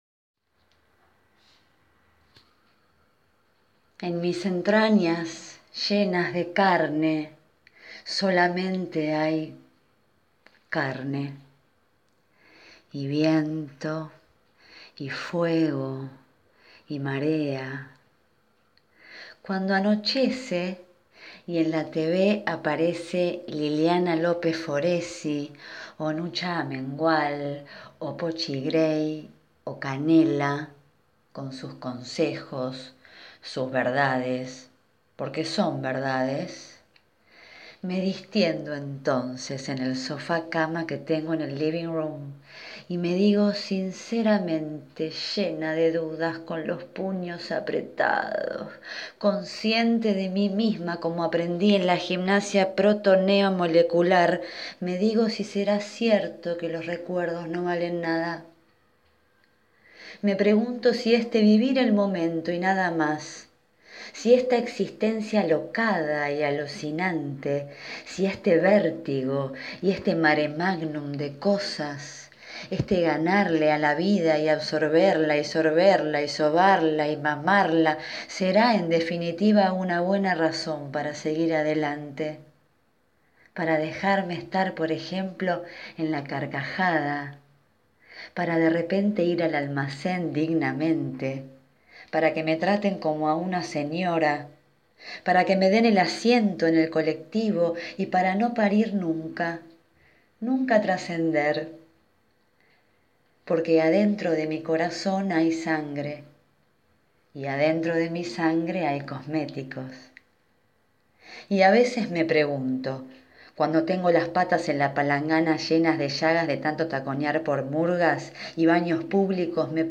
El tono cómico y denunciante del comienzo desciende como la vida misma y nos lleva al último pensamiento: todo es nada, todo es como la espuma de mar que siempre desaparece.